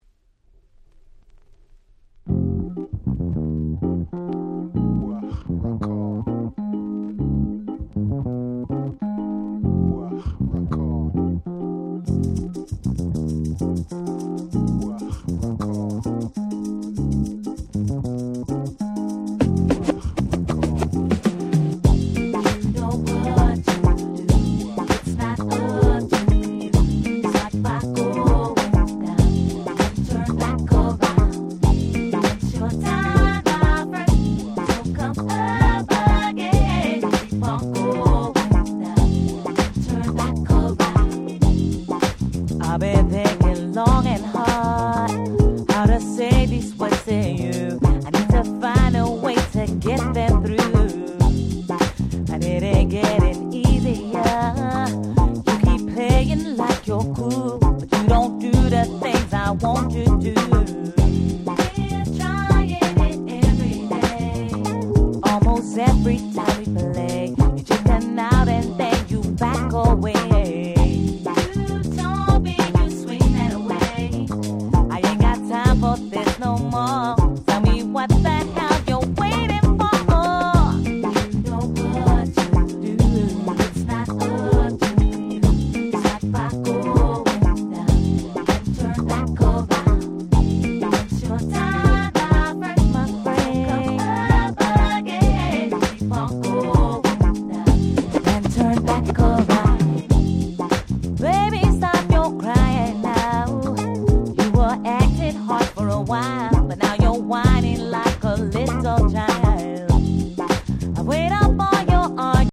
02' Nice UK Soul !!